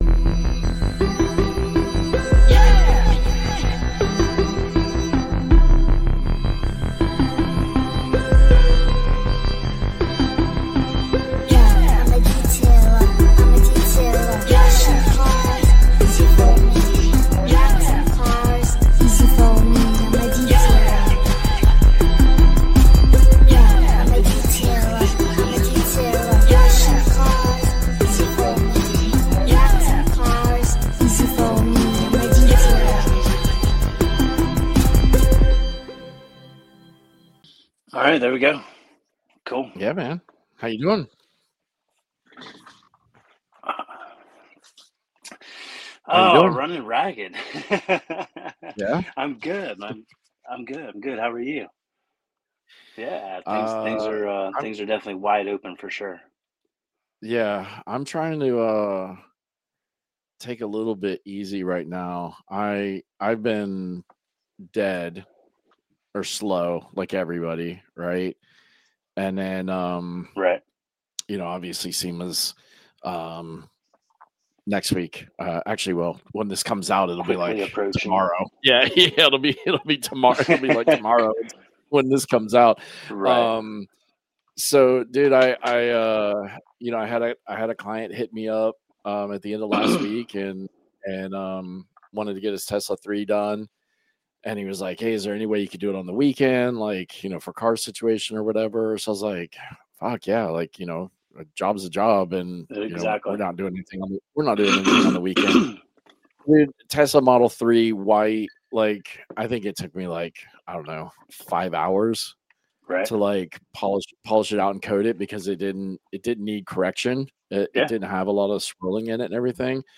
Out time got cut short on this episode due to his phone battery dying, but that just means I can get him on again.